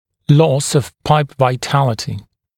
[lɔs əv pʌlp vaɪ’tælətɪ][лос ов палп вай’тэлэти]потеря жизнеспособности пульты